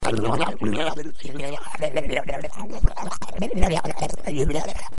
Descarga de Sonidos mp3 Gratis: balcucear balbuceo.
descargar sonido mp3 balcucear balbuceo